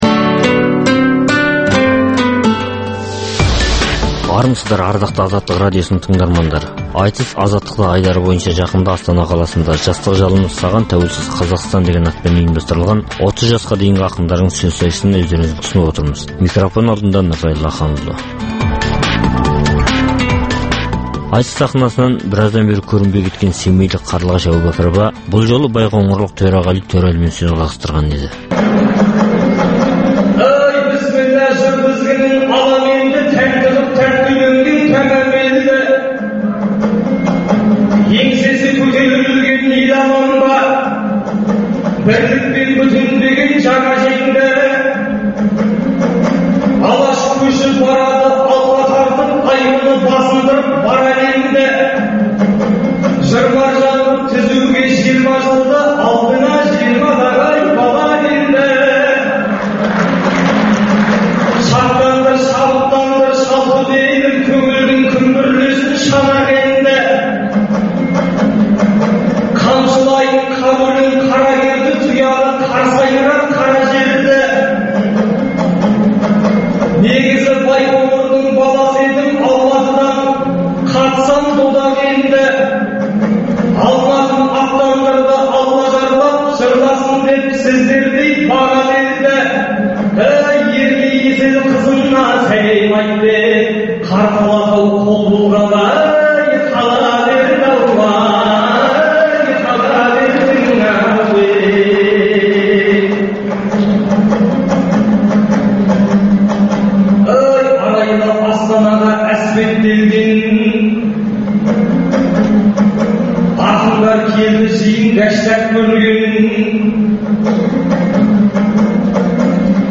Айтыс - Азаттықта
Қазақстанда әр уақытта өткізілетін ақындар айтысының толық нұсқасын ұсынамыз.